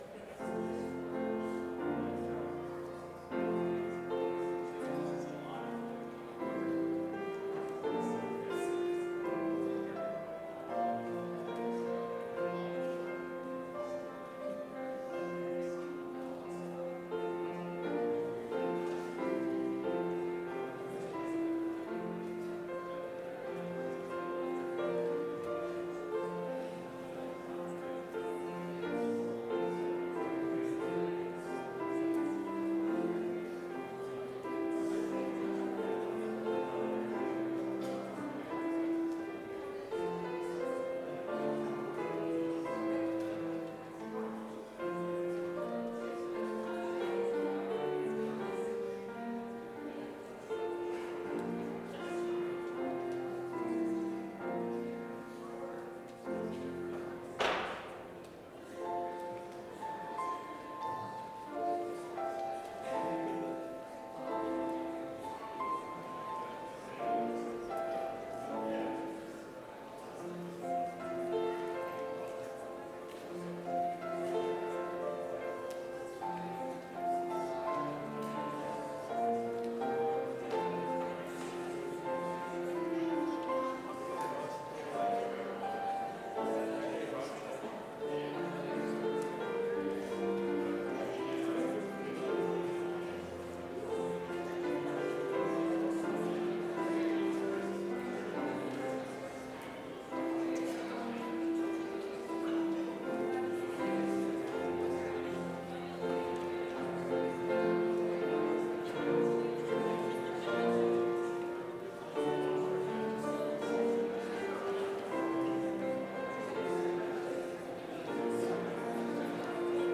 Complete service audio for Chapel - Wednesday, November 27, 2024